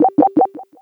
searchingBeep.wav